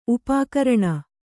♪ upākaraṇa